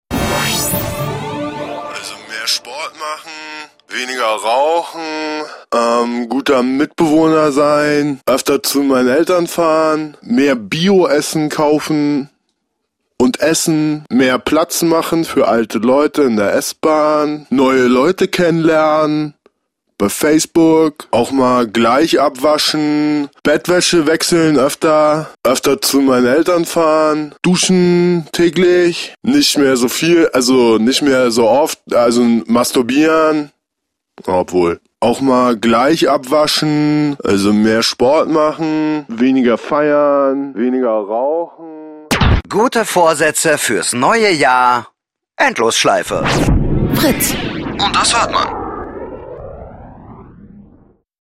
Endlosschleife "Vorsätze Neues Jahr" | Fritz Sound Meme Jingle